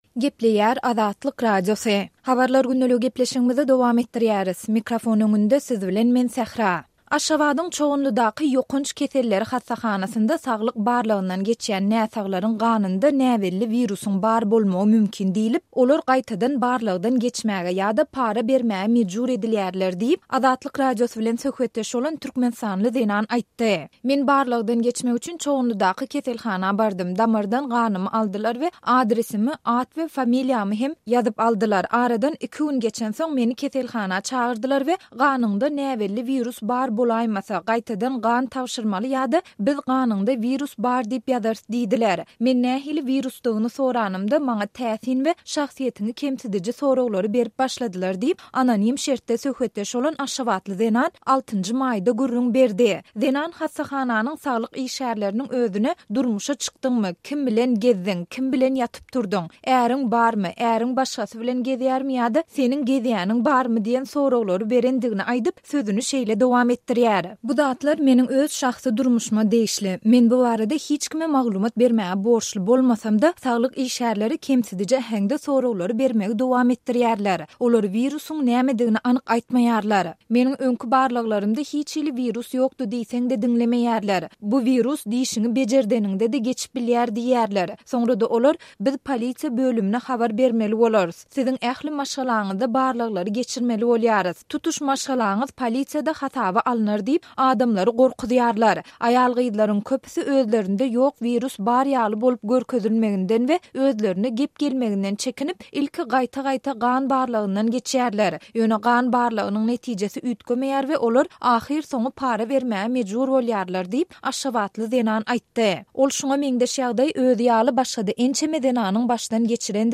Aşgabadyň Çoganlydaky ýokanç keselleri hassahanasynda saglyk barlagyndan geçýän näsaglaryň ganynda näbelli wirusyň bar bolmagy mümkin diýlip, olar gaýtadan barlagdan geçmäge ýa-da para bermäge mejbur edilýärler diýip, Azatlyk Radiosy bilen söhbetdeş bolan türkmenistanly zenan aýtdy.